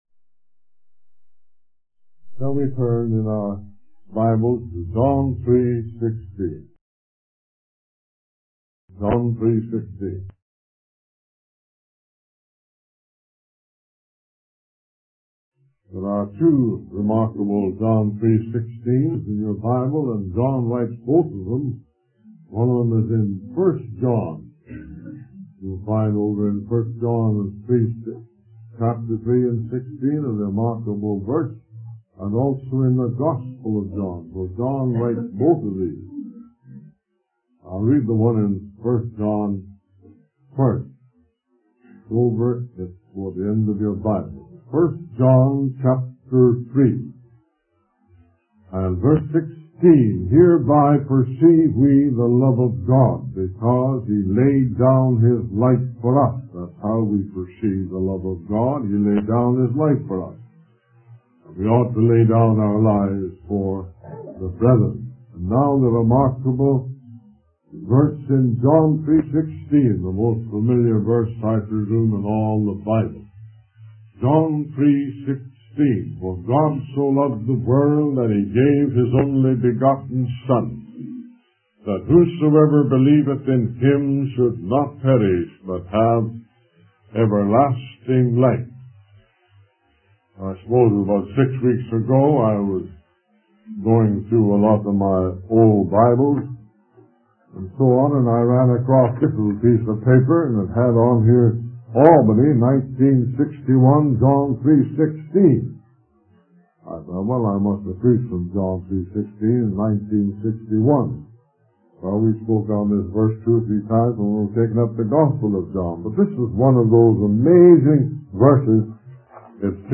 In this sermon, the preacher focuses on John 3:16 and the love of God. He emphasizes that God's love is perfect and can cast out fear, which is prevalent in the world today. The preacher uses the analogy of an explorer trying to measure the depth of the ocean to illustrate the unfathomable depth of God's love.